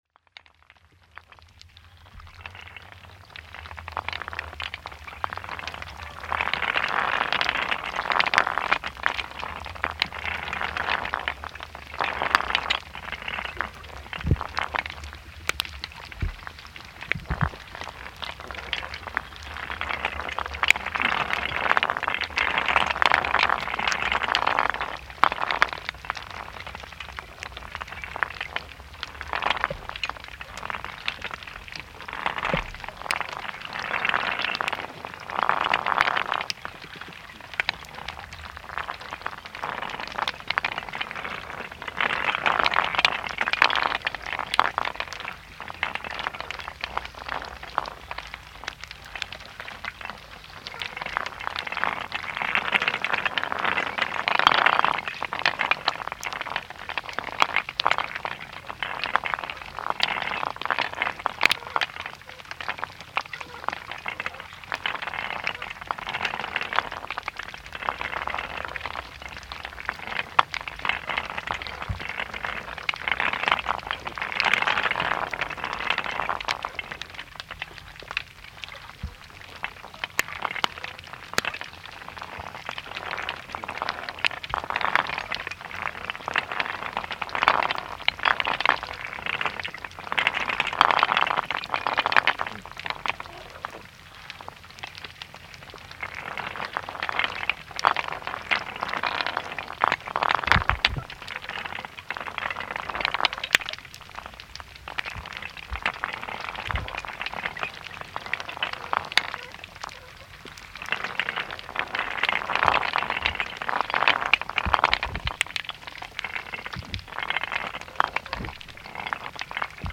Posted in Náttúra, tagged Aquarian H2a-XLR, Gufunes, Kayak, Korg MR1000, Neðansjávarupptaka, Sound devices 552, Undersea recording on 28.8.2011| 4 Comments »
Hljóðin sem hér heyrast voru tekin upp fimmtudaginn 11. ágúst 2011, austan við bryggjukantinn við Áburðarverksmiðjuna . Hljóðin sem þarna heyrast geta komið frá rækjum, skeljum og hrúðurkörlum en hæst heyrist í einhverju sem líkist því að verið sé að róta í möl á botninum. Þá heyrast líka hljóð sem hugsanlega koma frá sel eða hval og tíst sem líkist tísti hjá auðnutitlingi. Þó það hafi verið stafalogn á meðan á upptöku stóð þá eru drunurnar líklega tilkomnar vegna þess að létt gola leikur um hljóðnemakaplana.
Á 12. mínútu kemur stór hópur kajakræðara fyrir hornið á viðlegukantinum. Þá heyrist greinilega í áratökunum á leið þeirra inn í Eiðsvík.
Most of the sounds are pops and crackles, possibly from shrimps and/or shells.
Other sounds are easy to explain like diving ducks searching food and small waves at nearby beach.